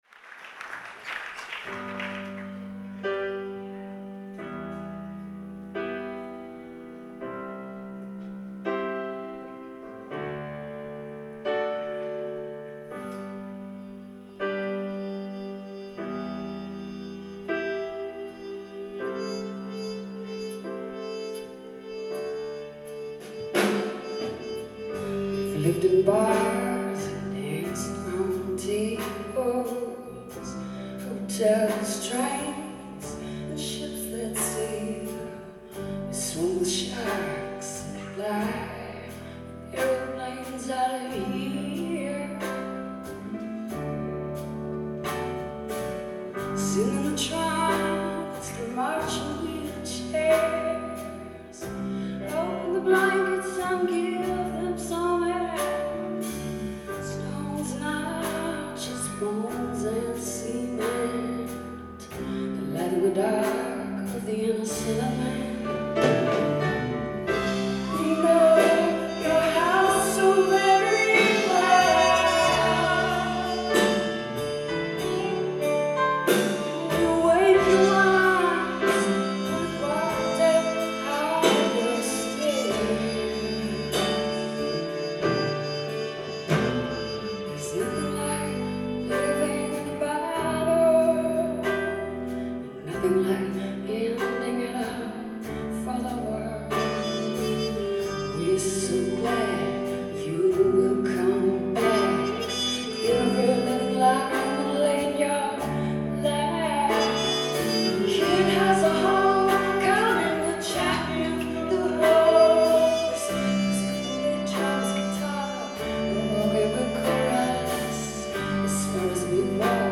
Live at the Berklee Performance Center
Boston, MA